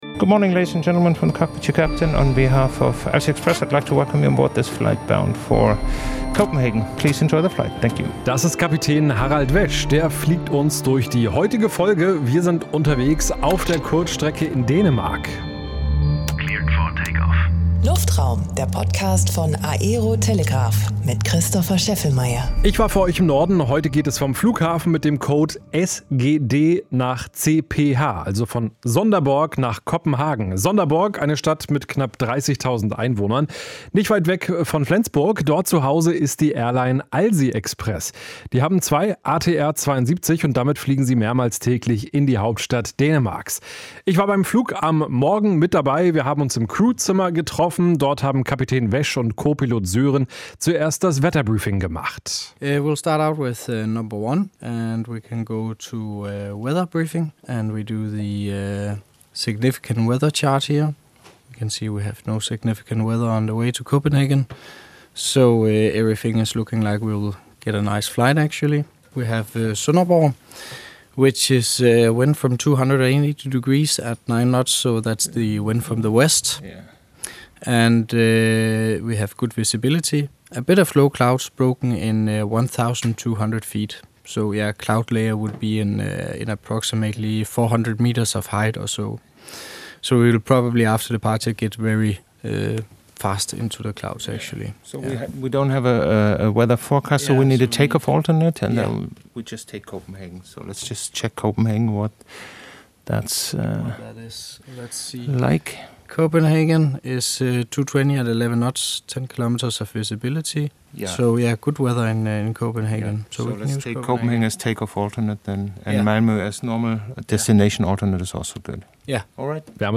In dieser Folge erlebt ihr die Flugvorbereitungen und den Start.